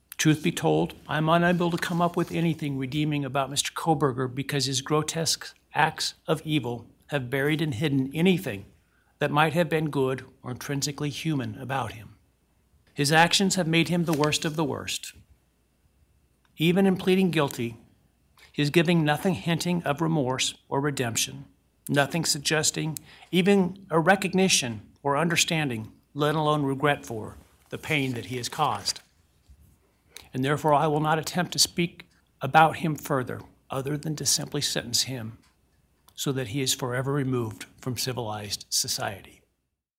The confessed quadruple murderer Bryan Kohberger will spend the rest of his life in prison after being sentenced today (Wed) at the Ada County Courthouse in Boise.
In delivering his sentence, Judge Hippler was unable to find anything positive to say about Kohberger.